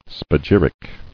[spa·gyr·ic]